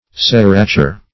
Search Result for " serrature" : The Collaborative International Dictionary of English v.0.48: Serrature \Ser"ra*ture\, n. [L. serratura a sawing, fr. serrare to saw.] 1.
serrature.mp3